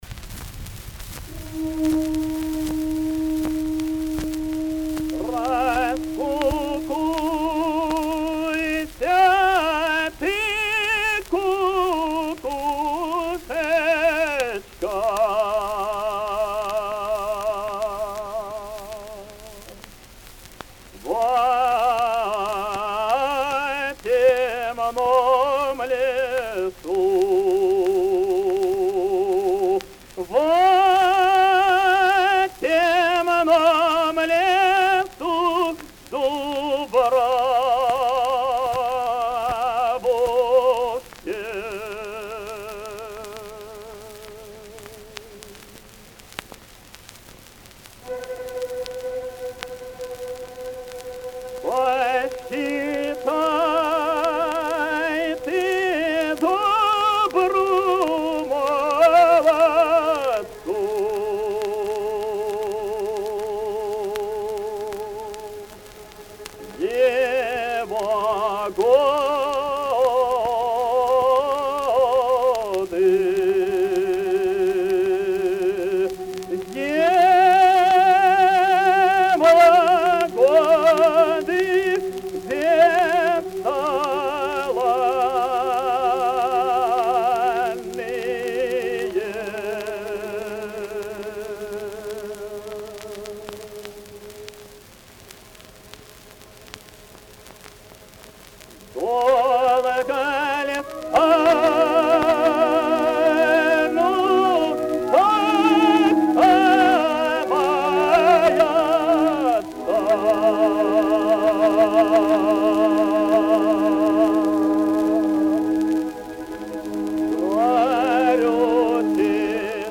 Опера «Псковитянка». Песня Михайло Тучи. Исполняет В. П. Дамаев.
Обладал сильным и гибким драматическим тенором широкого диапазона, ровно звучавшим во всех регистрах и позволявшим певцу успешно справляться с разнообразным лирико-драматическим и героическим репертуаром.